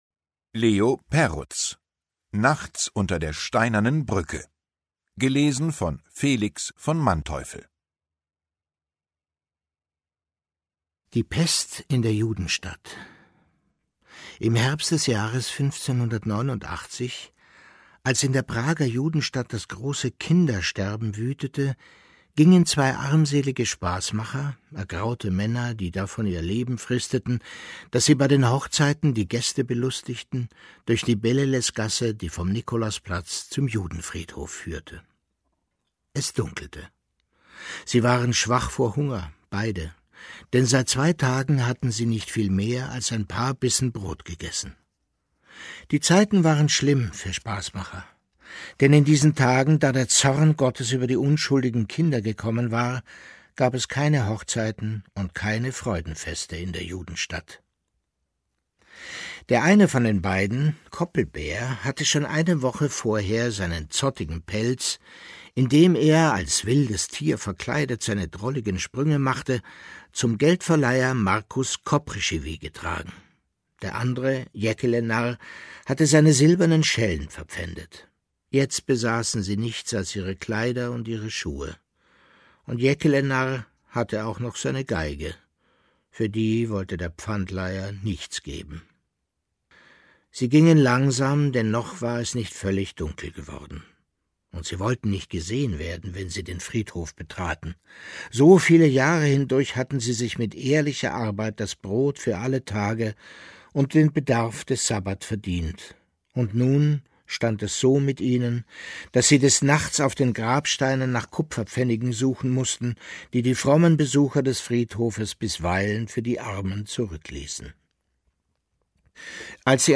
Ungekürzte Lesung mit Felix von Manteuffel (1 mp3-CD)
Felix von Manteuffel (Sprecher)